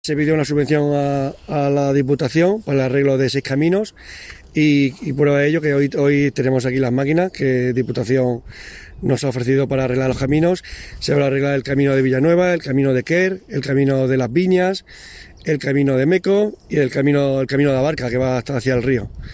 Declaraciones del concejal Antonio Expósito